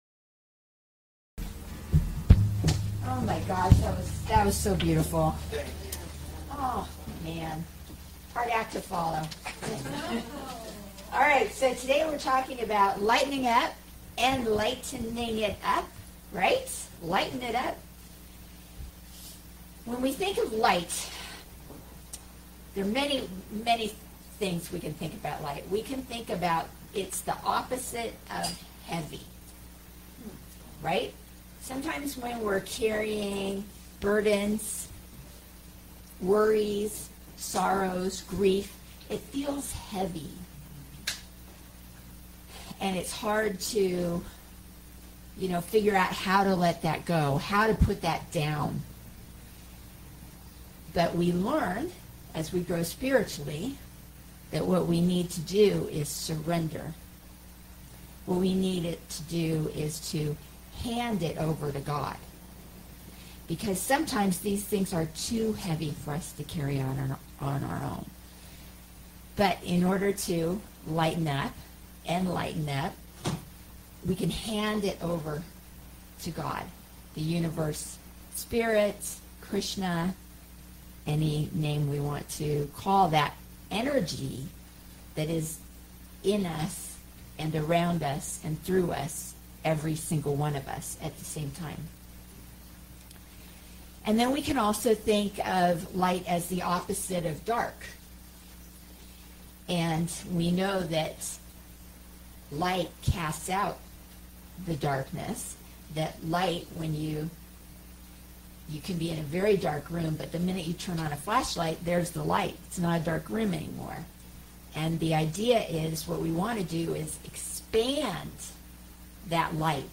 Enlighten Up – talk at CSL Pleasant Valley